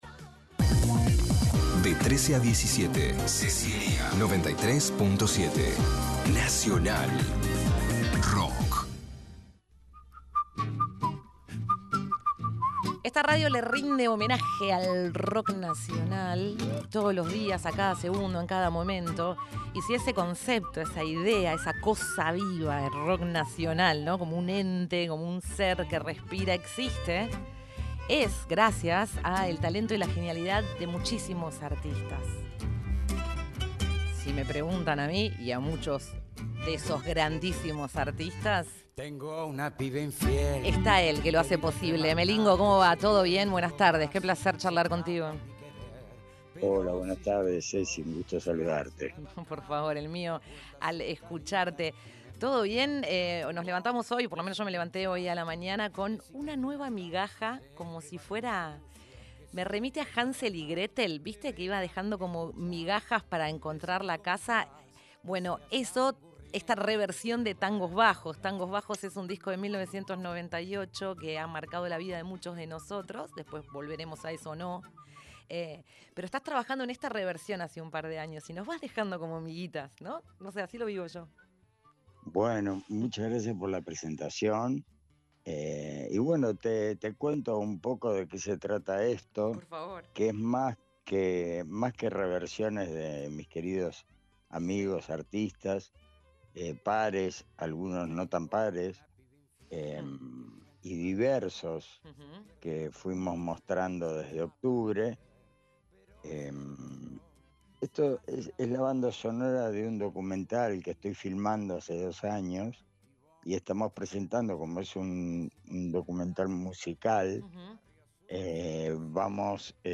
Entrevista Daniel Melingo presenta su "Encuentro Maximalista"